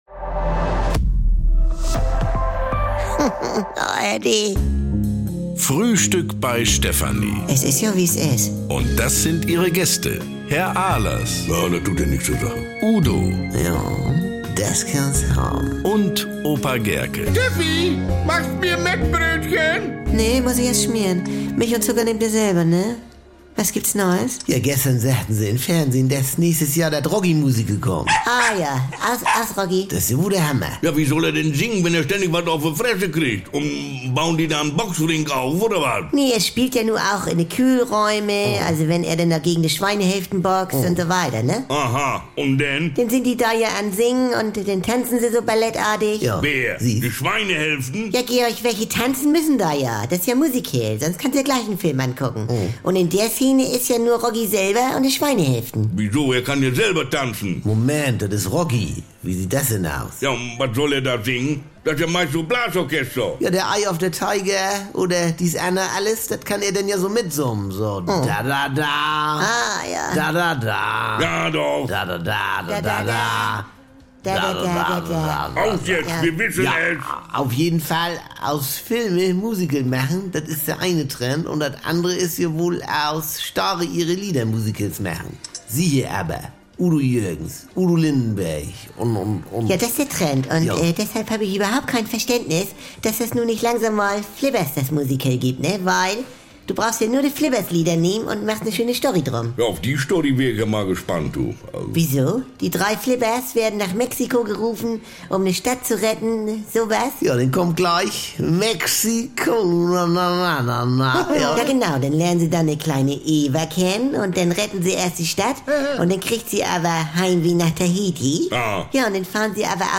Frisch geschmierte Mettbrötchen, Schnorrer-Tipps, Pyro-Fantasien und brummeliges Gemecker bekommt ihr jeden Tag im Radio oder jederzeit in der ARD Audiothek.